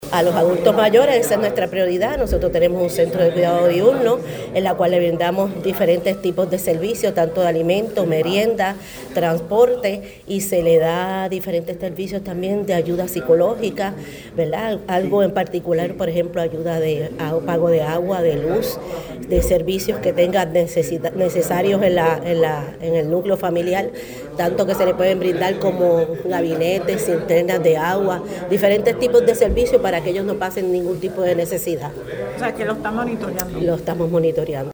(Gurabo, 25 de febrero de 2026)-De acuerdo a la alcaldesa del municipio de Gurabo, Vimarie Peña Dávila, los adultos mayores es una prioridad.